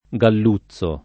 galluzzo [ g all 2ZZ o ]